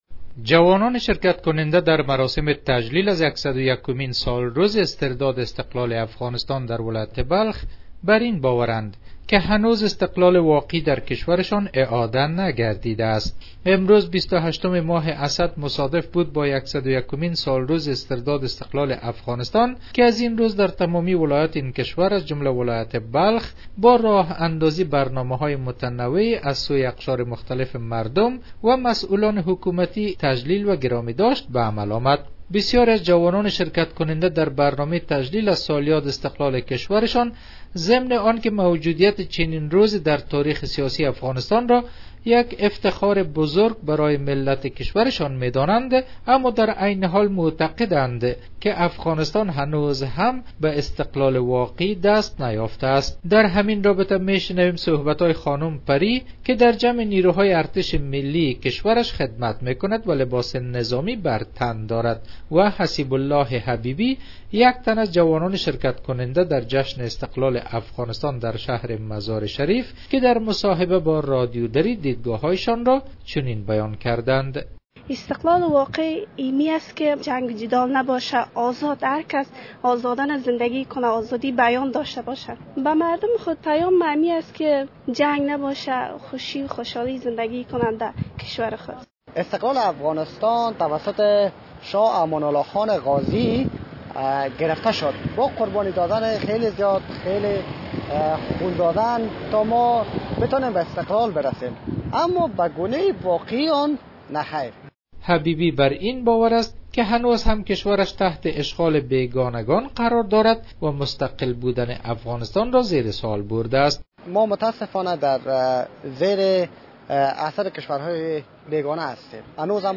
به گزارش خبرنگار رادیودری، جوانان شرکت کننده در مراسم تجلیل از یکصد و یکمین سالروز استرداد استقلال افغانستان در ولایت بلخ بر این باورند که هنوز استقلال واقعی در کشورشان اعاده نگردیده است.